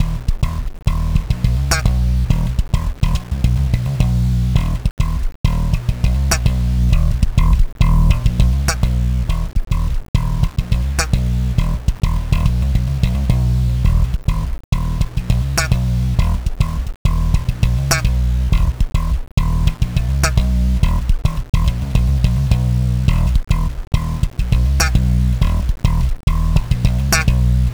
Same sound with 6 bit quantification and no dither noise 00' 27.7" dith010 868 KB